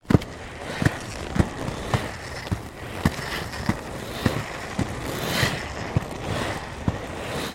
Звуки роликовых коньков